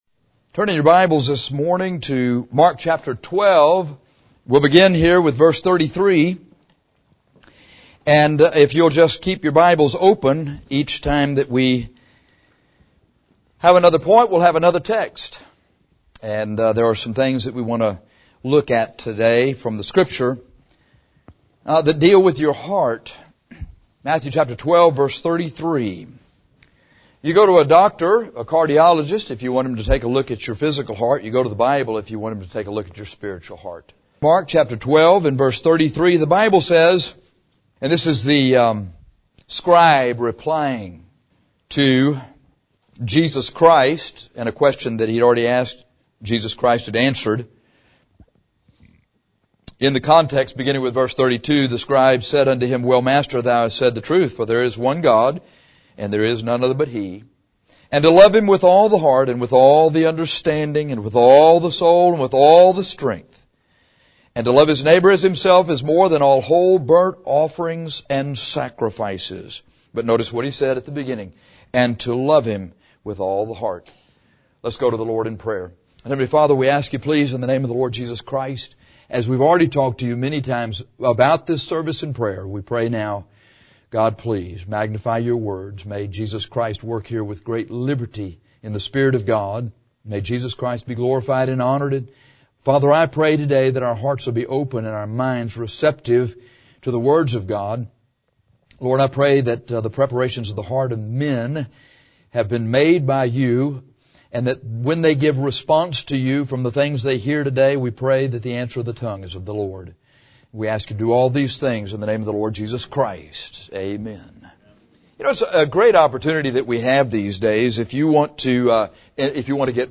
Put Your Whole Heart Into It Mark 12:33 - Bible Believers Baptist Church